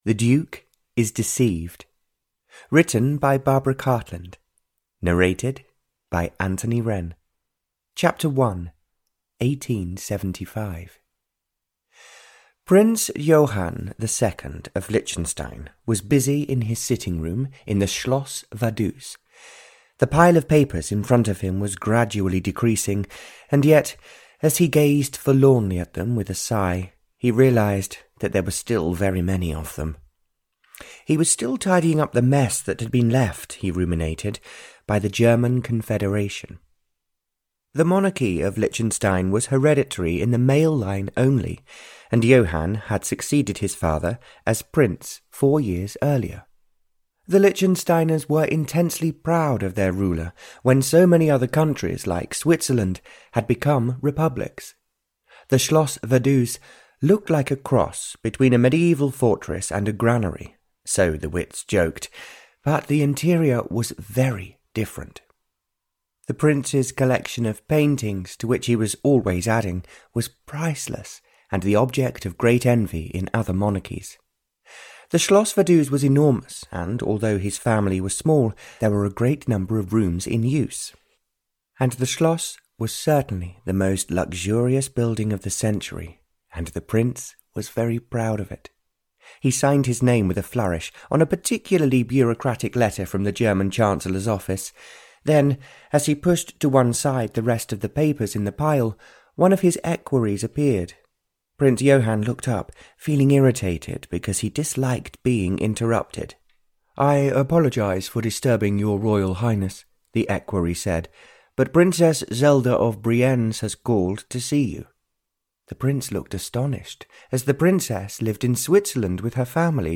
Audio knihaThe Duke is Deceived (Barbara Cartland's Pink Collection 97) (EN)
Ukázka z knihy